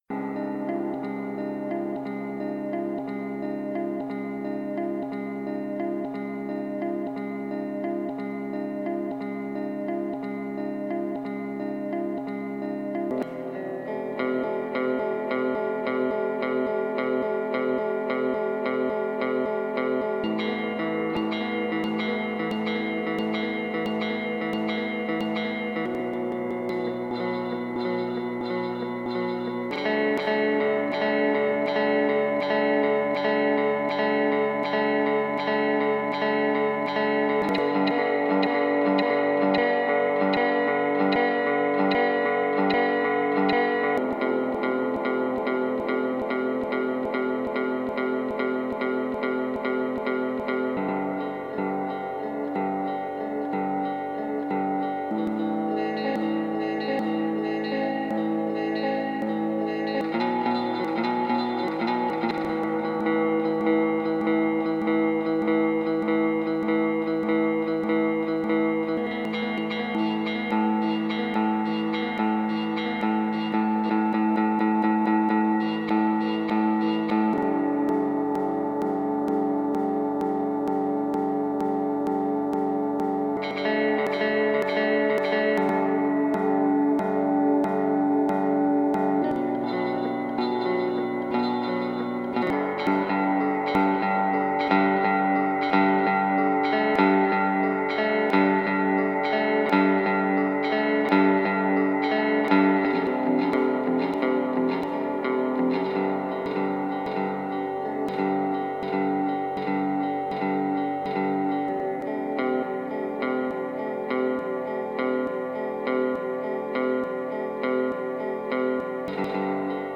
ambient glitch guitar drone